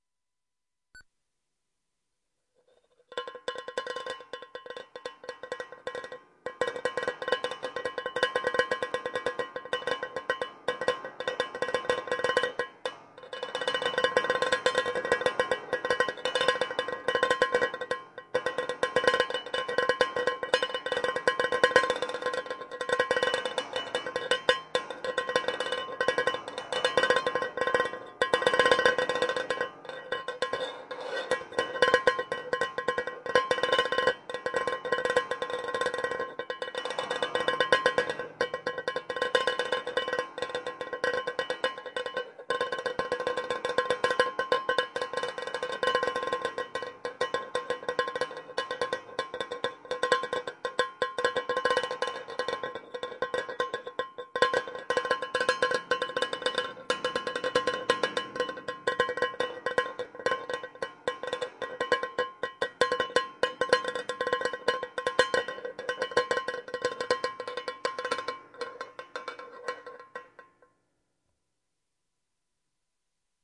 球囊放气 10116a
描述：一个瘦小的气球在被从泵中取出后突然放气。 当我摸索着气球时，开始有一些呼吸的、嘶嘶的声音
标签： 气球 放气 速度快 嘶嘶声 突然
声道立体声